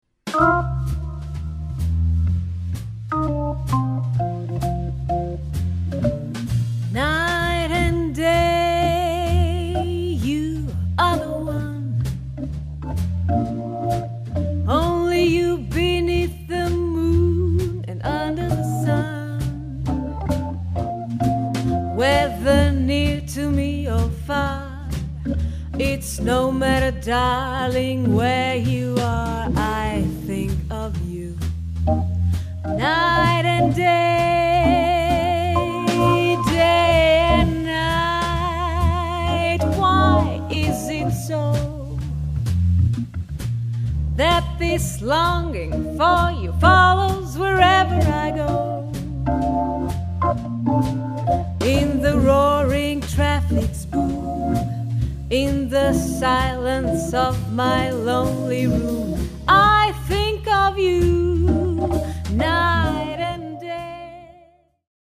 QUARTETT
voc/piano(Orgel)/bass/dr